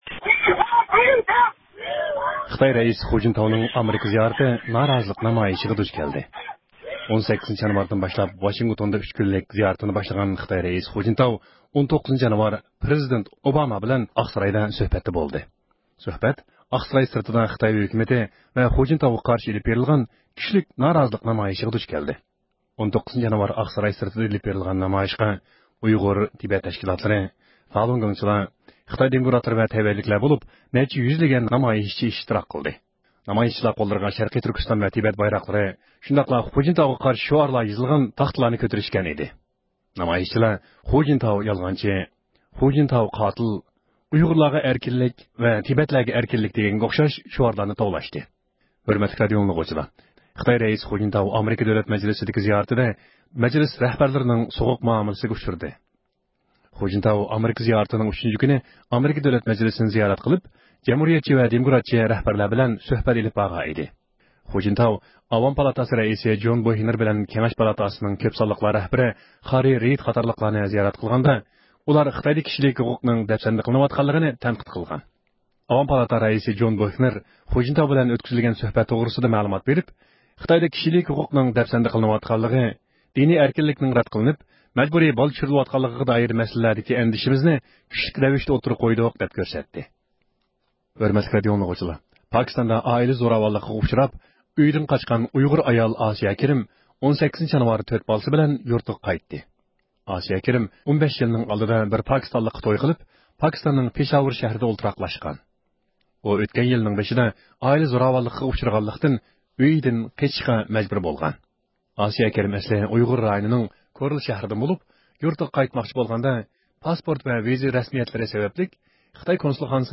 ھەپتىلىك خەۋەرلەر (15-يانۋاردىن 21-يانۋارغىچە) – ئۇيغۇر مىللى ھەركىتى